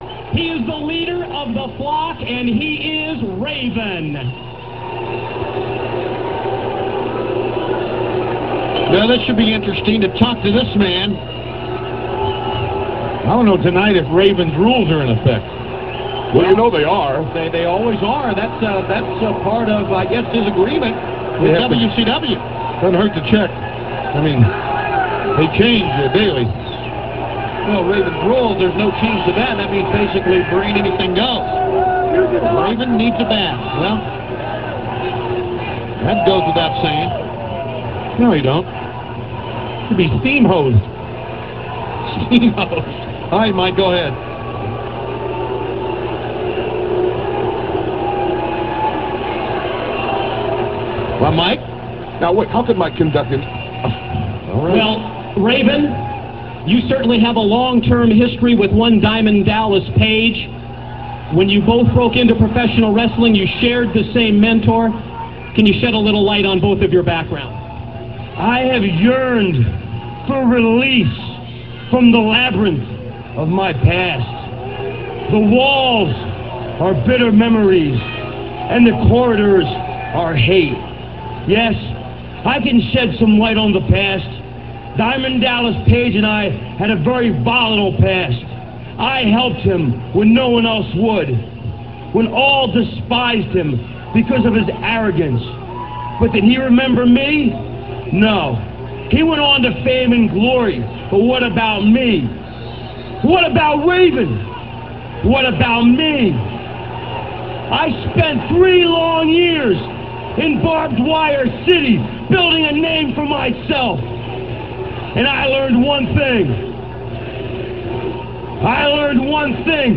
- This interview comes from Thunder - [3.5.98]. Raven talks with Mike Tenay about how he spent 3 long years in 'Barbed Wire City' [ECW] - (3:11)